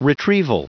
Prononciation du mot retrieval en anglais (fichier audio)
retrieval.wav